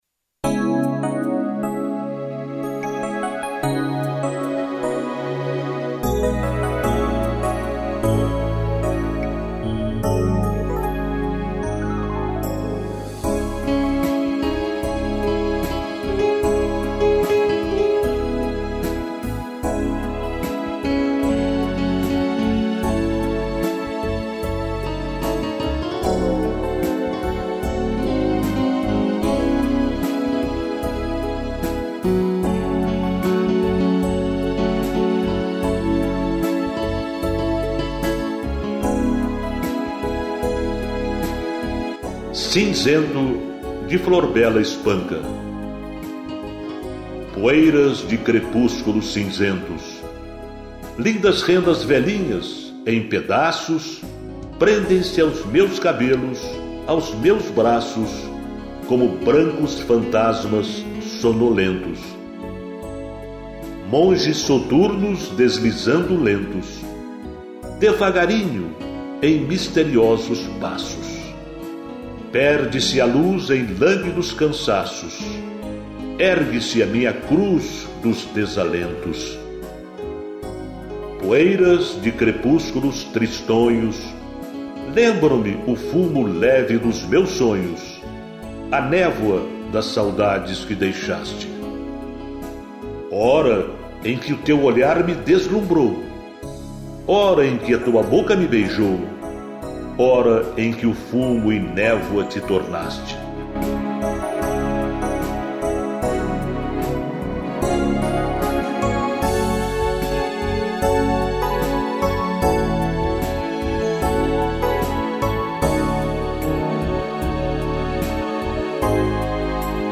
interpretação do texto